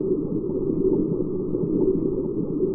sci-fi_forcefield_hum_loop_07.wav